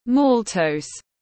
Đường mạch nha tiếng anh gọi là maltose, phiên âm tiếng anh đọc là /ˈmɔːl.təʊz/